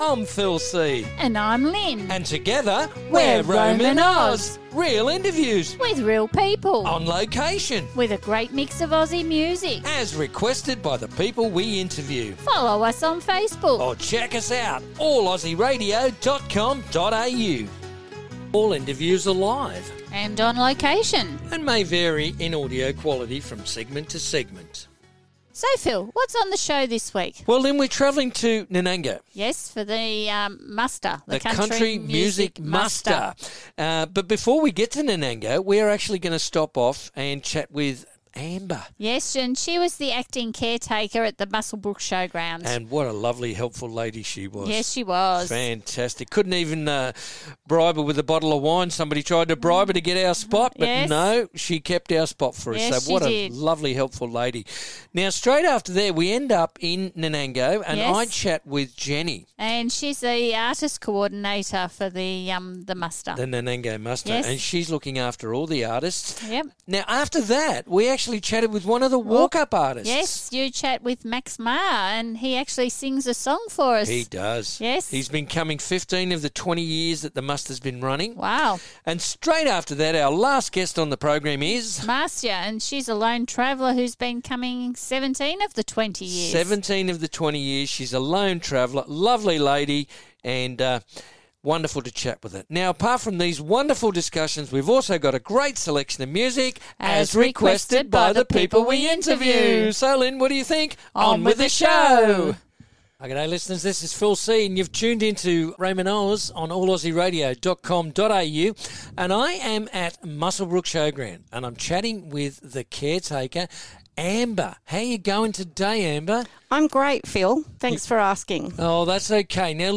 On the show this week we are visiting Nanango for the Country Music Muster.